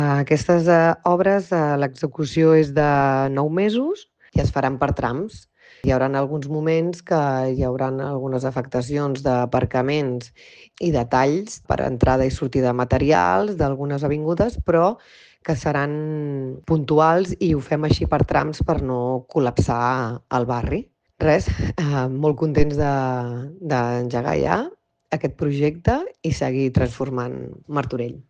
Elisabeth Plaza, regidora de Serveis Urbans i Via Pública